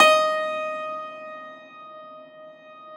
53e-pno15-D3.aif